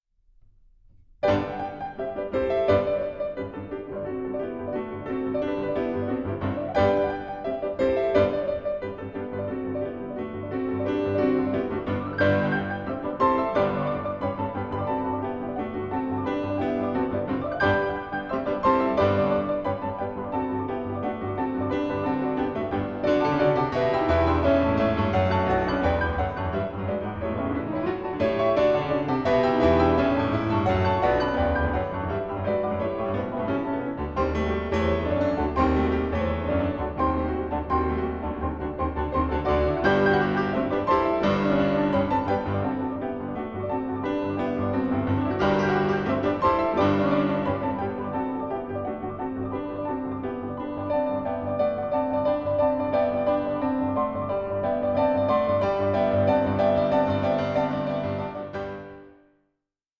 Suite for Piano Duo